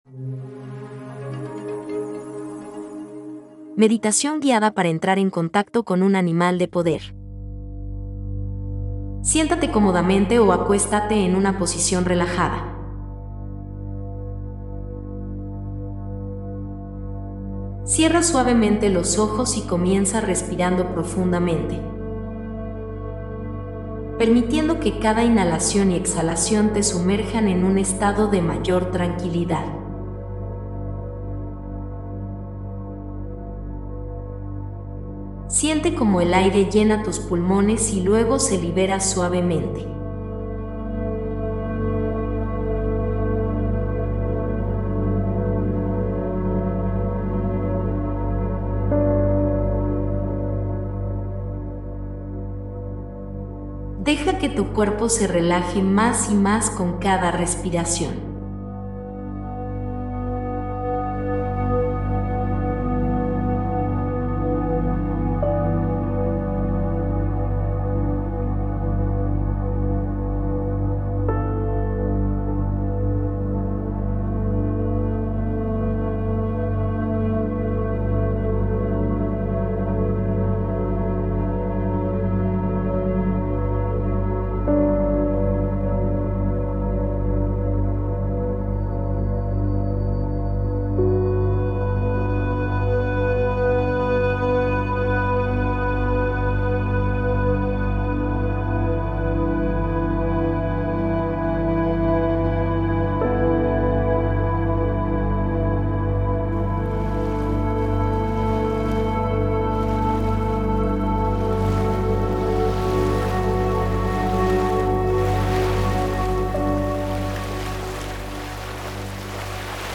Elige si prefieres escuchar el audio de la meditación o ver el video ¿CÓMO ES LA TORTUGA MARINA?